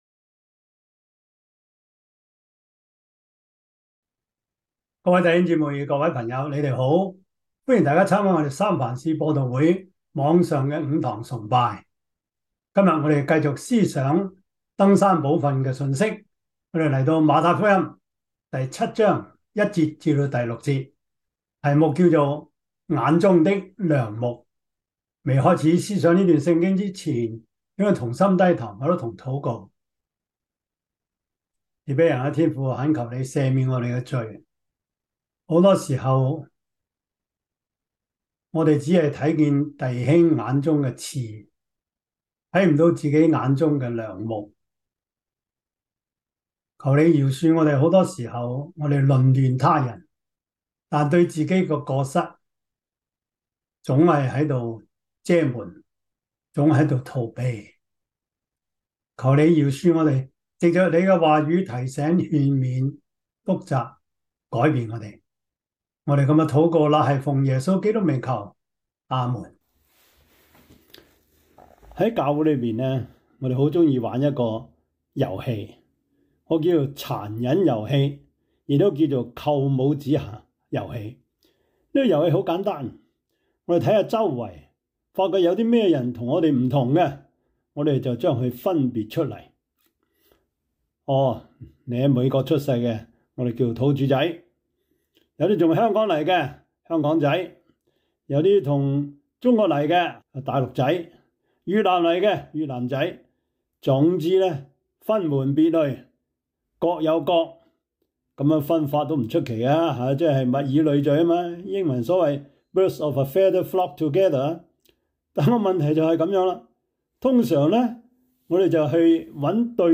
Topics: 主日證道 « 恩典的記號 基礎神學 – 第三課 »